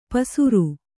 ♪ pasuru